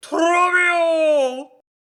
trobbio Meme Sound Effect
This sound is perfect for adding humor, surprise, or dramatic timing to your content.